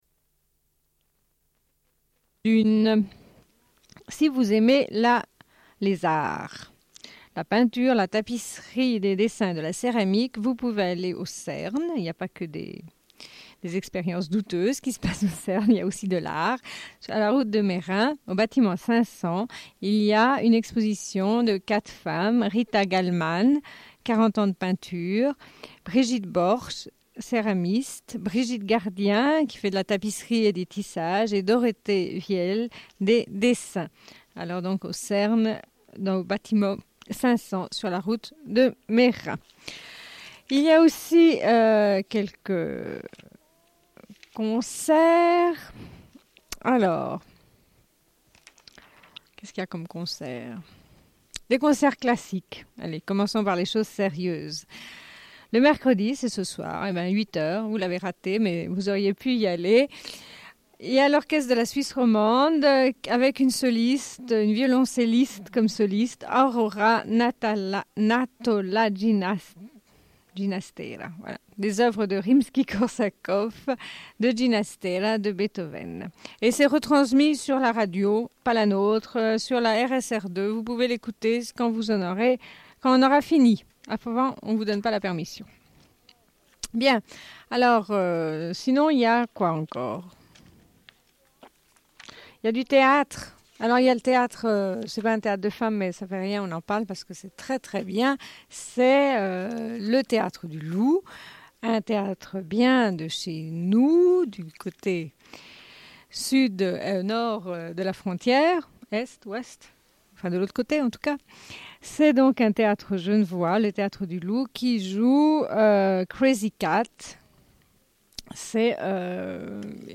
Une cassette audio, face B47:31